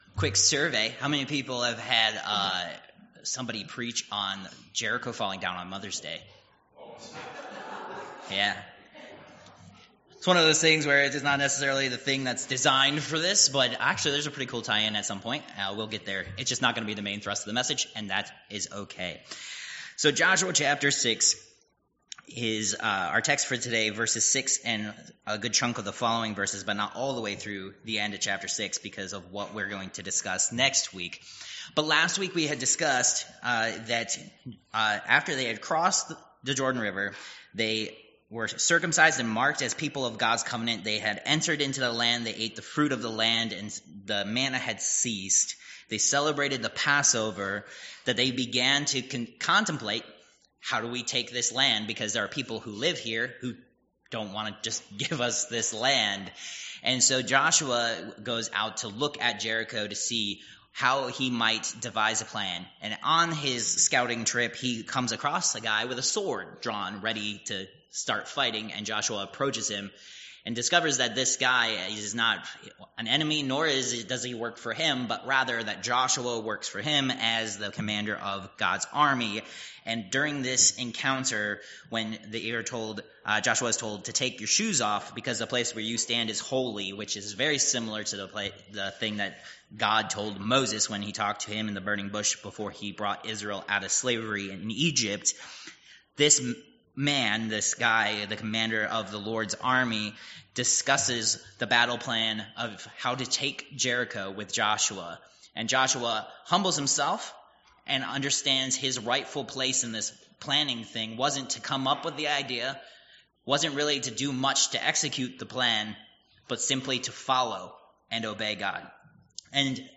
Josh. 6:6-27 Service Type: Worship Service Download Files Notes « Protected